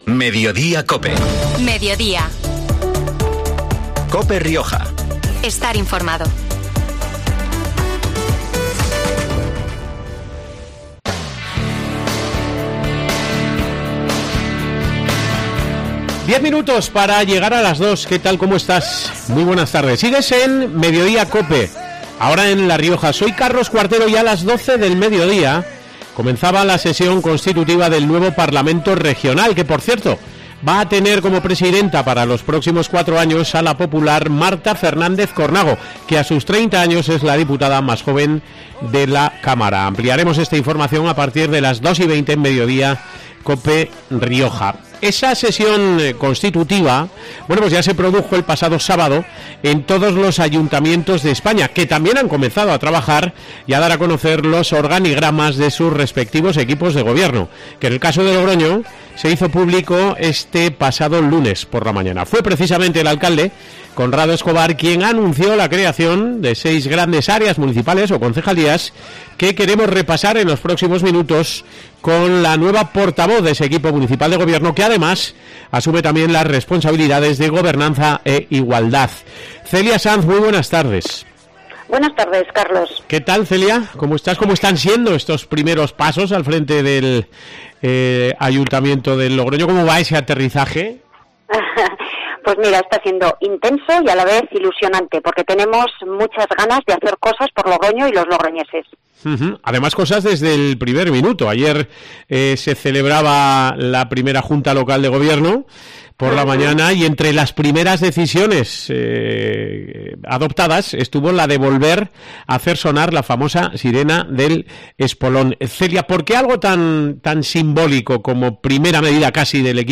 La nueva portavoz del equipo de Gobierno en el Ayuntamiento de Logroño, Celia Sanz, ha estado este 22 de junio en COPE Rioja para analizar las primeras decisiones adoptadas por el gabinete del popular Conrado Escobar, entre las que se encuentra la de volver a hacer sonar la sirena de Ibercaja en el Espolón.